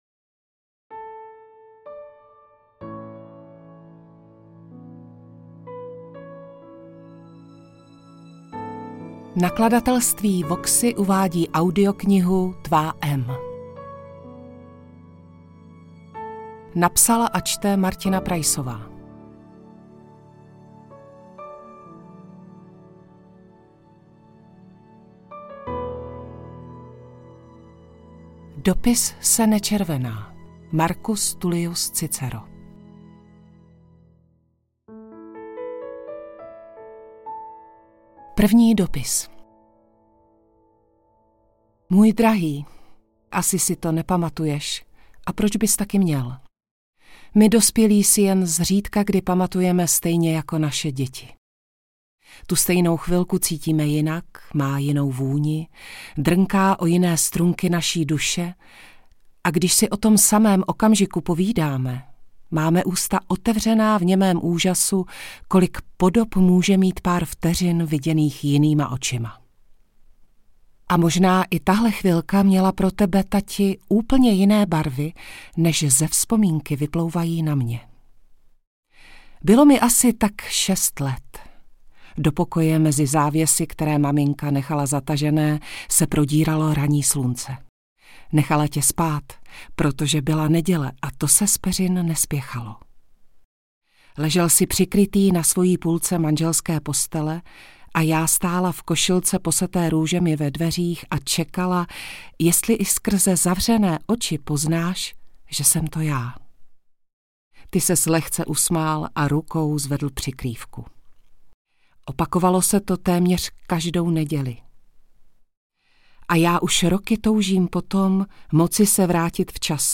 Interpret:  Martina Preissová
AudioKniha ke stažení, 25 x mp3, délka 3 hod. 38 min., velikost 198,4 MB, česky